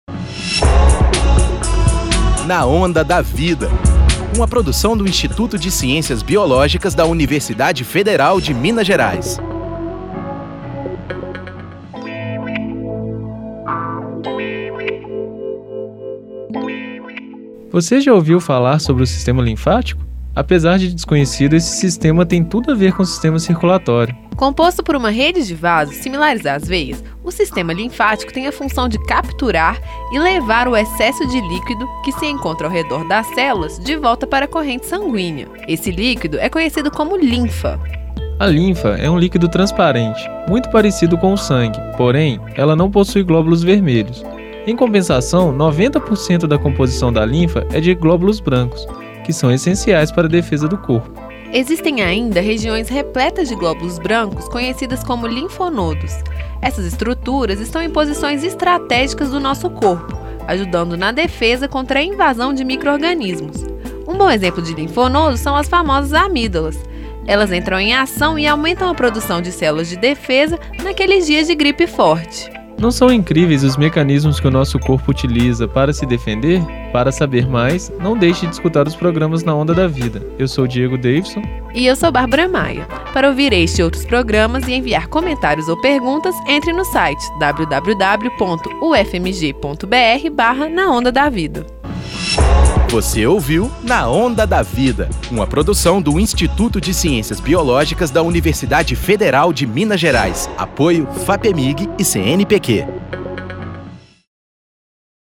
Na Onda da Vida” é um programa de divulgação científica através do rádio